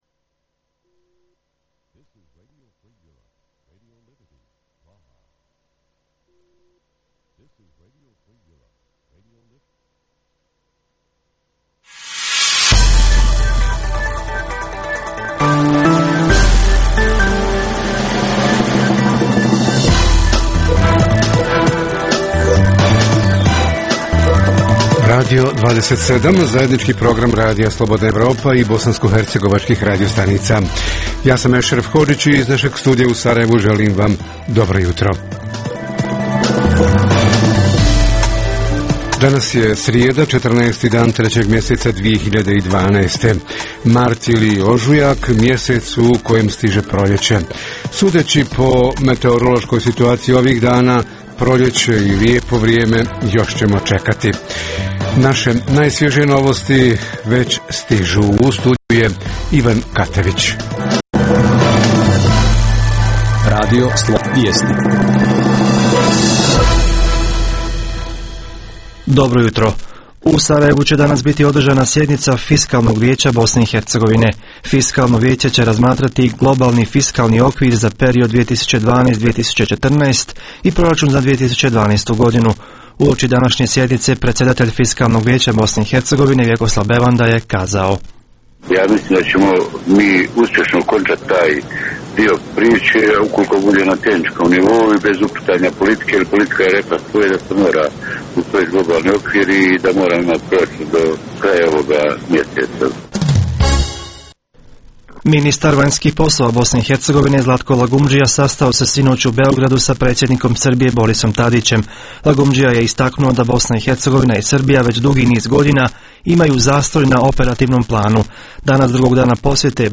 Uoči proljetne sjetve – šta i koliko država duguje poljoprivrednicima, a šta i koliko poljoprivrednici državi – kako će sve to uticati na obim proljetne sjetve? Reporteri iz cijele BiH javljaju o najaktuelnijim događajima u njihovim sredinama.